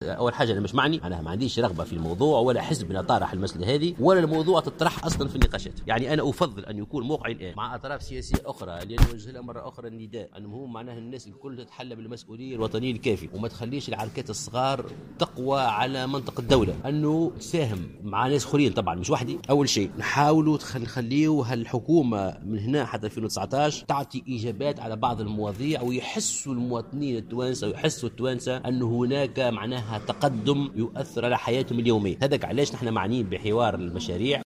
وقال في تصريح لمراسل "الجوهرة اف أم" على هامش ندوة نظمها حزبه في العاصمة، إنه غير معني بهذا المنصب وليست لديه رغبة في حقيبة وزارية.